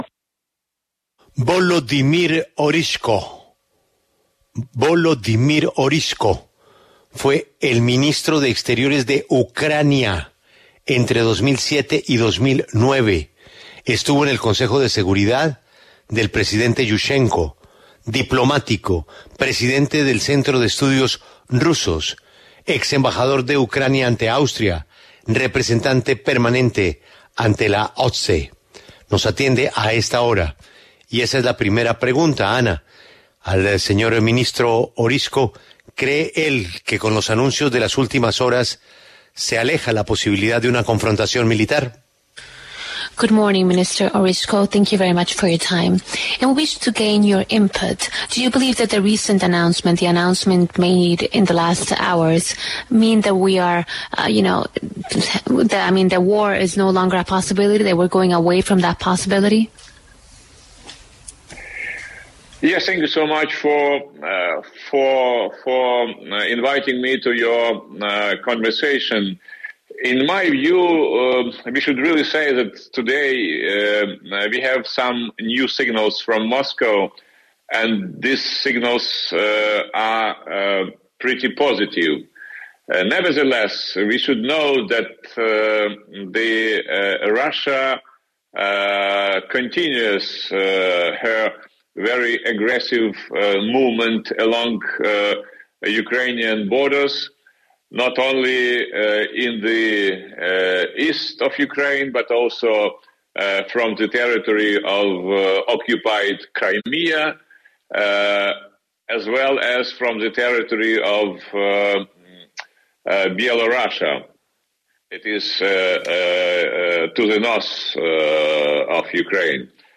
Volodymyr Ohryzko, exministro de Asuntos Exteriores de Ucrania, habló en La W a propósito de la crisis en la frontera con Rusia.